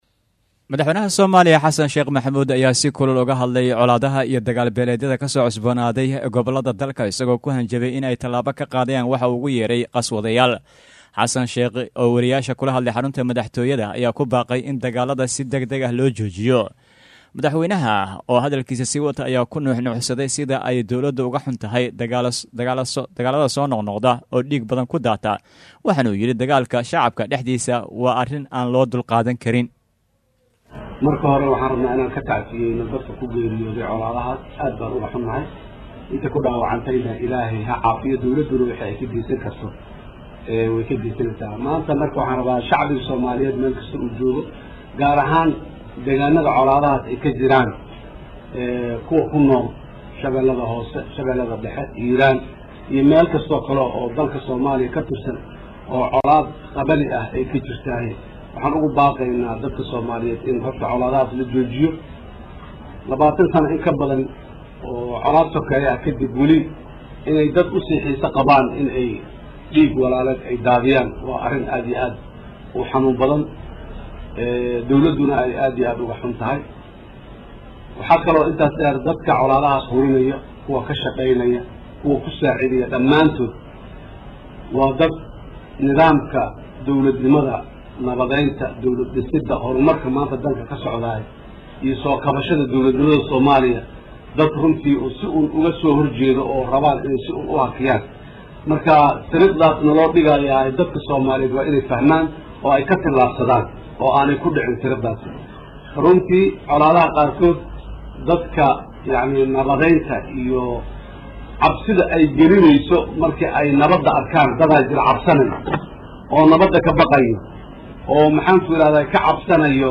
Hadalka Madaxwayne Xasan Sheekh